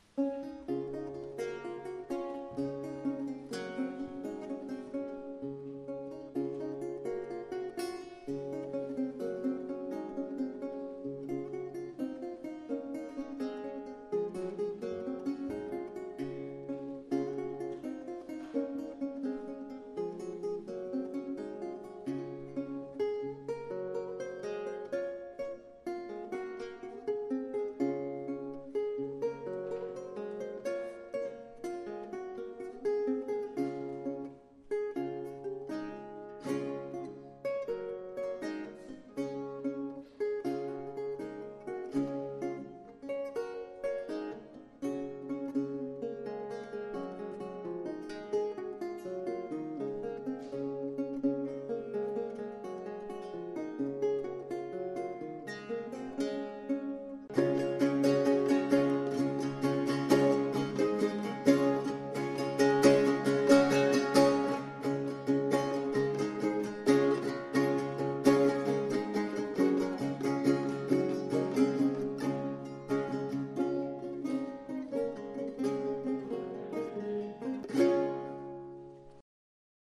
La Guitare Baroque
Enregistré à l'église d'Arberats (64120) le 24 Juillet 2012